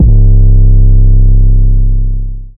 DEEDOTWILL 808 61.wav